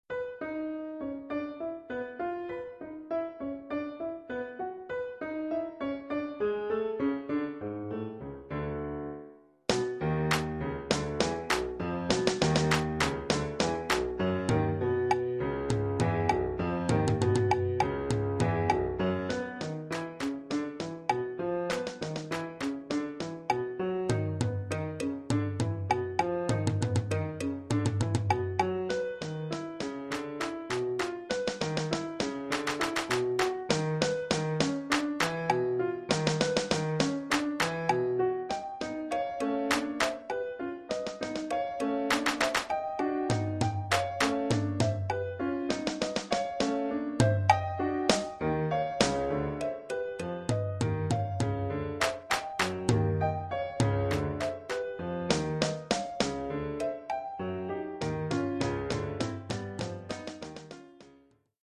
Collection : Percussions
Oeuvre pour caisse claire,
tom basse et piano.